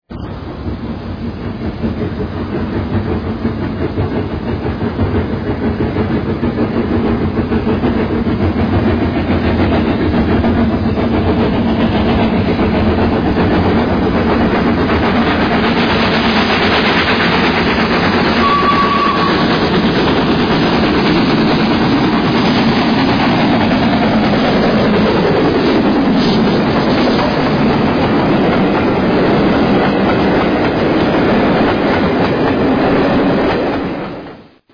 Sounds of L. N. E. R. steam locomotives